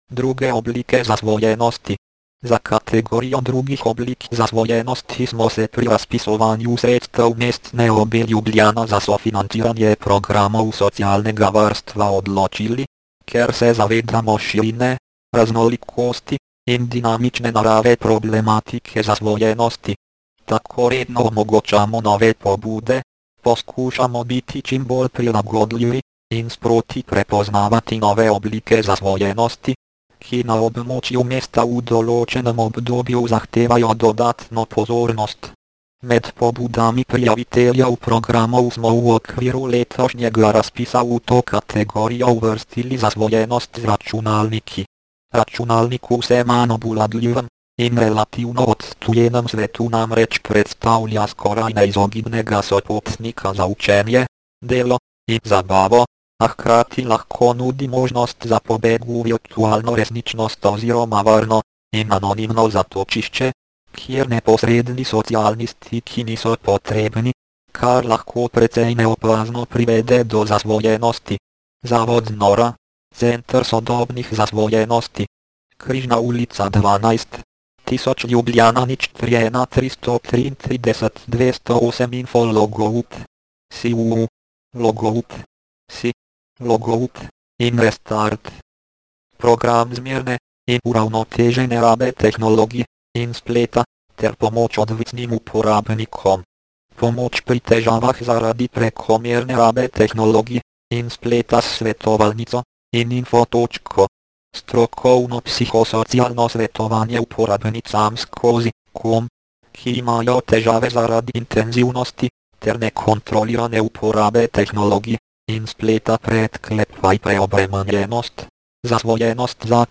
Sintetizator govora Proteus TTS - dokumenti